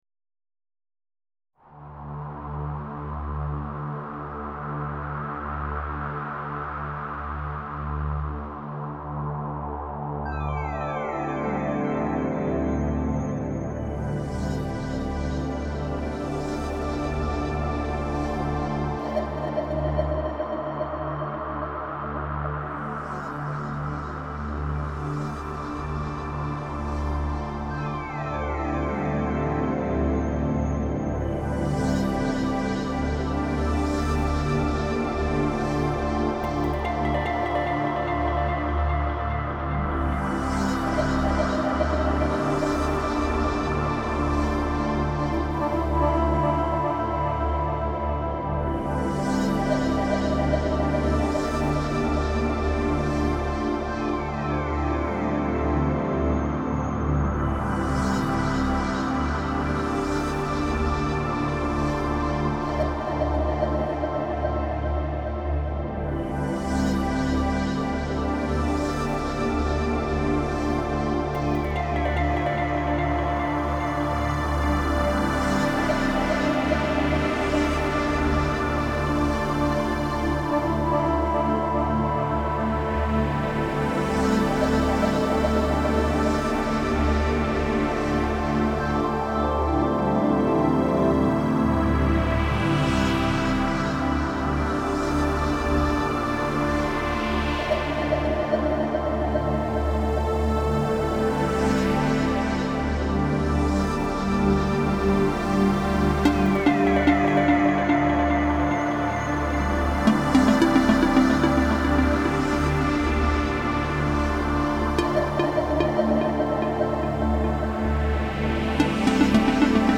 Downtempo/Chillout, Ambient Genre